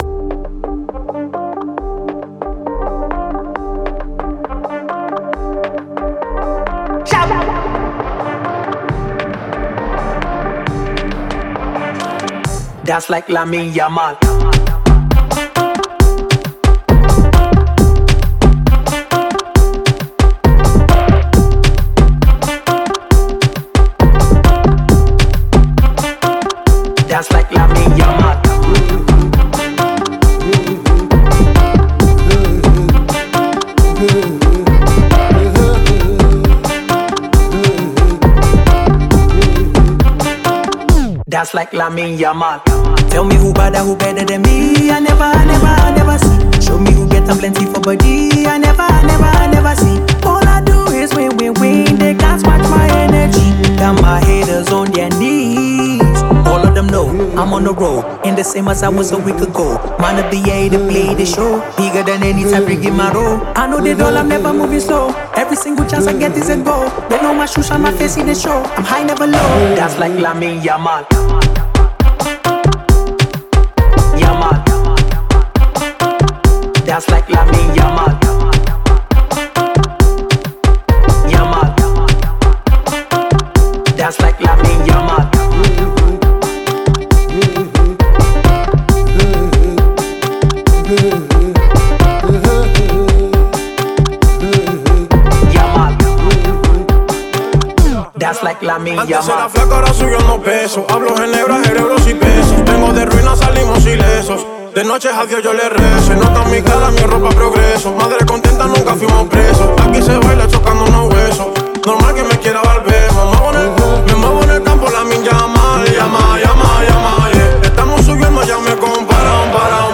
electrifying jam
With an upbeat tempo and vibrant production
brings party energy and catchy hooks